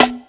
Snares
Daddy Snare.wav